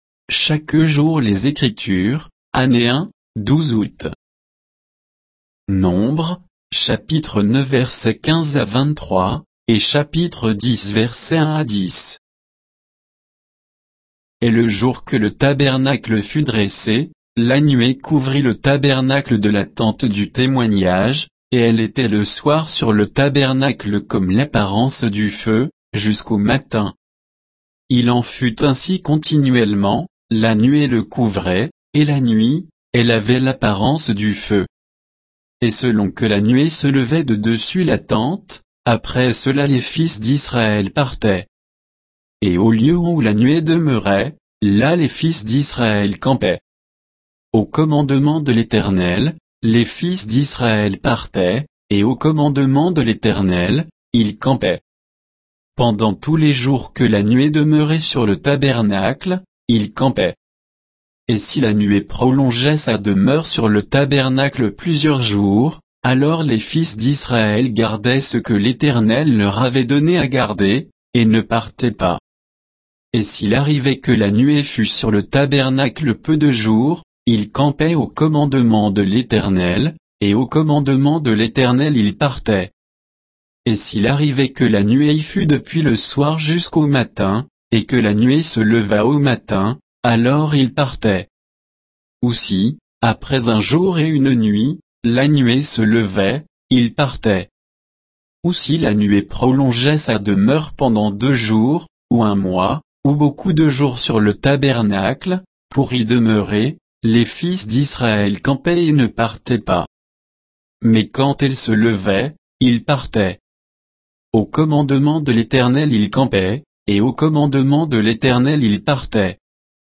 Méditation quoditienne de Chaque jour les Écritures sur Nombres 9, 15 à 10, 10